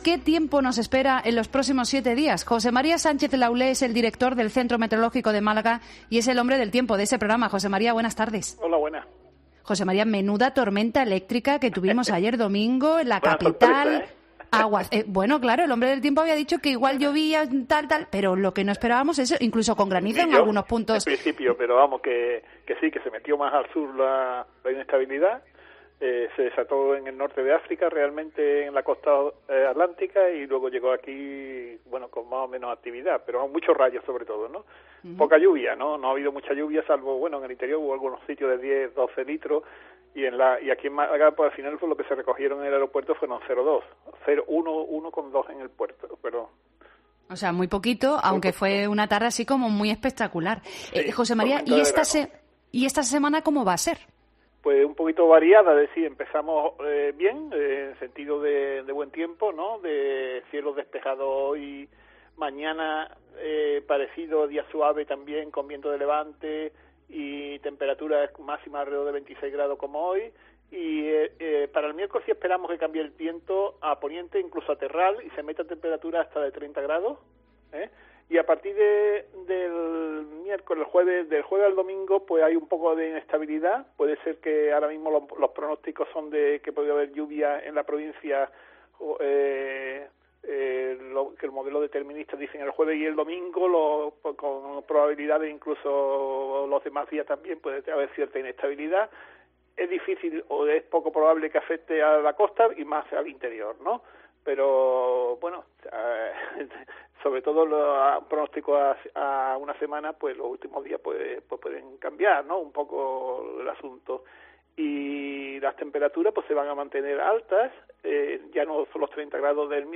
PREVISIÓN METEOROLÓGICA